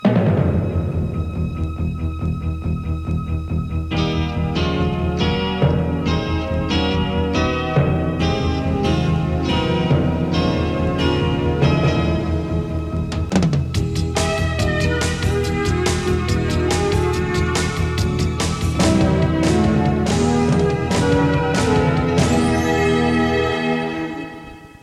Música identificativa